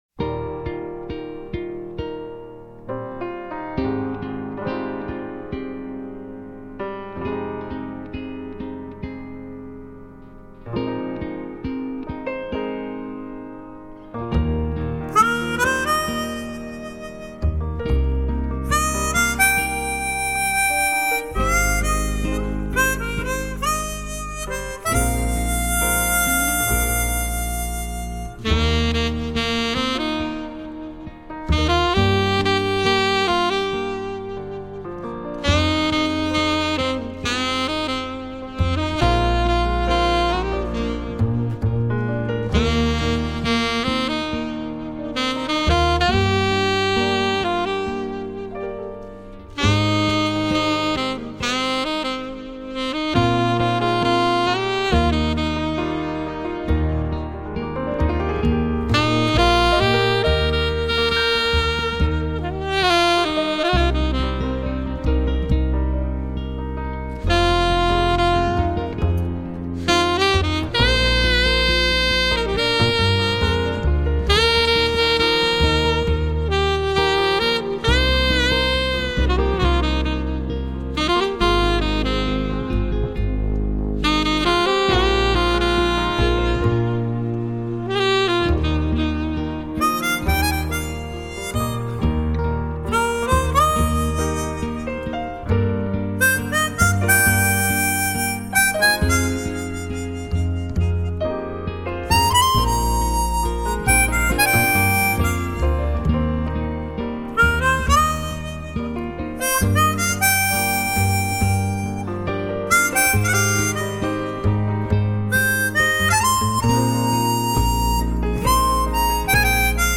乐队互动演奏
深情演绎 直触心灵 乐队伴奏 荡气回肠
麻雀虽小 三款不同萨克斯音色同台示范